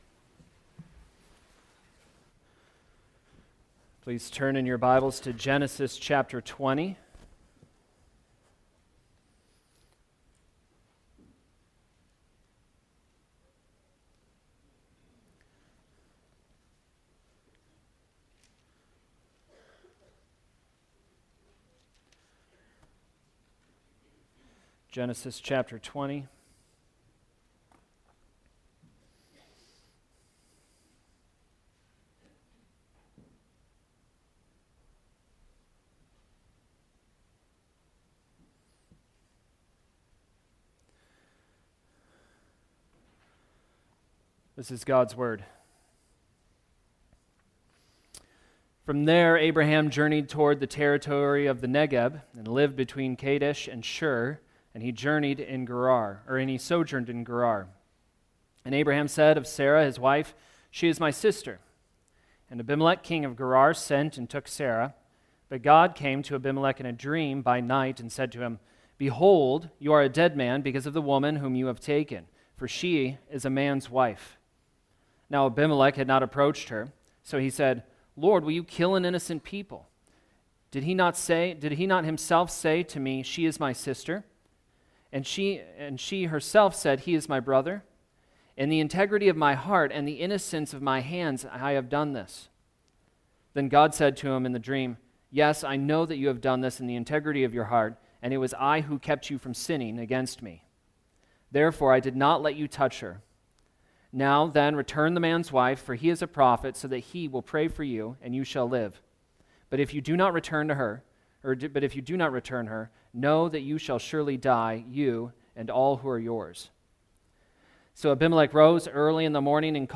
Genesis Sermons